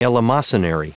Prononciation du mot eleemosynary en anglais (fichier audio)
Prononciation du mot : eleemosynary
eleemosynary.wav